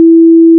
**🔊 SFX PLACEHOLDERS (23 WAV - 1.5MB):**
**⚠  NOTE:** Music/SFX are PLACEHOLDERS (simple tones)
door_close.wav